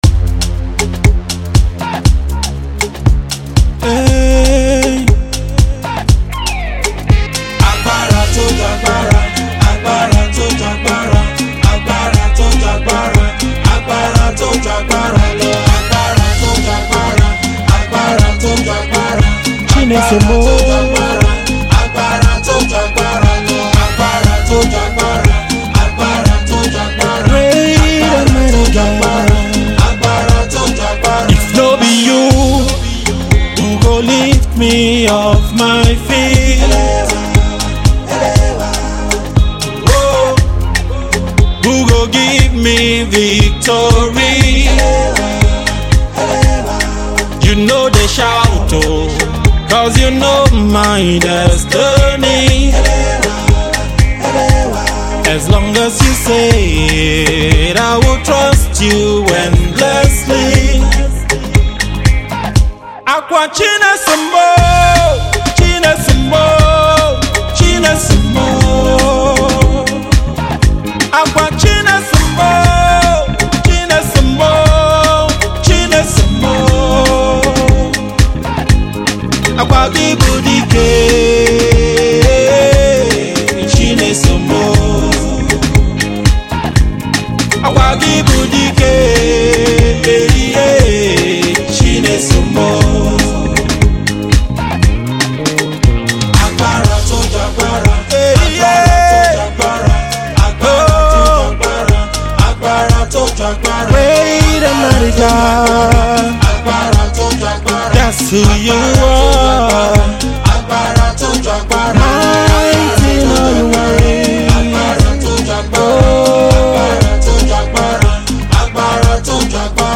Energetic Nigerian-Canadian Gospel Singer
awestruck Afro-beat song